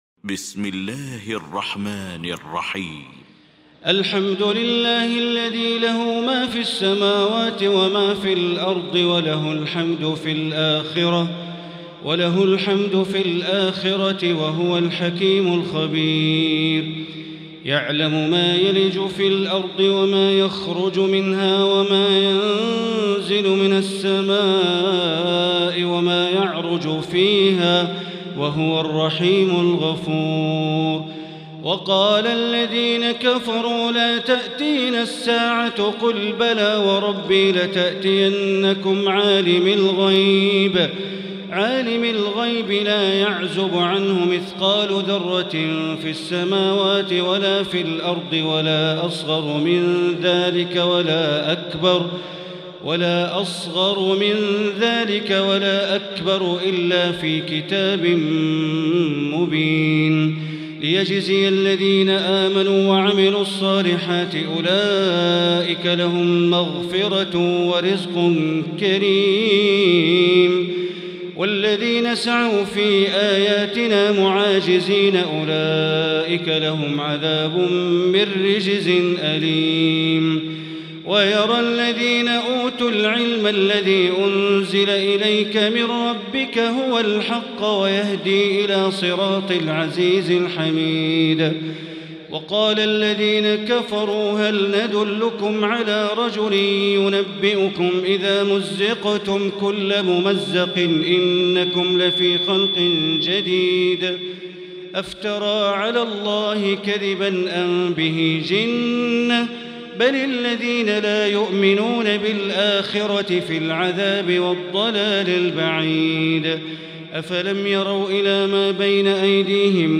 المكان: المسجد الحرام الشيخ: معالي الشيخ أ.د. بندر بليلة معالي الشيخ أ.د. بندر بليلة سبأ